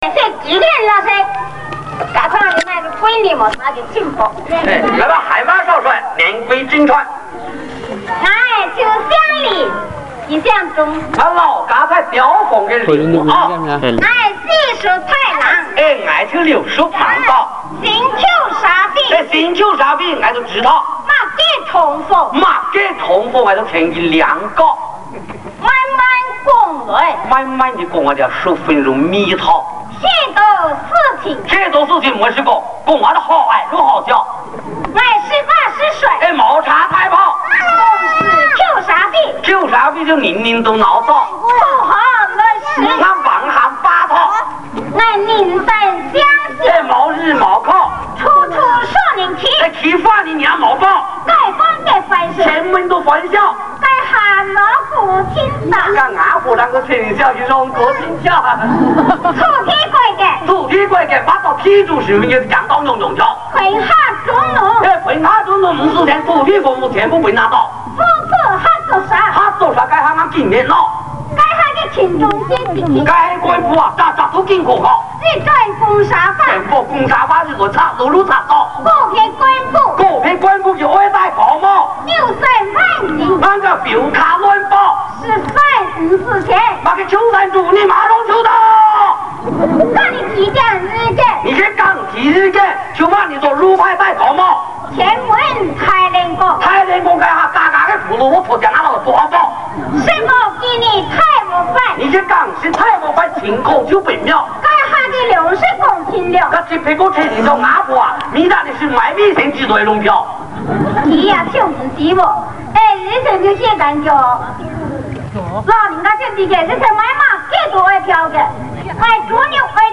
（现场版） - 客家传统歌曲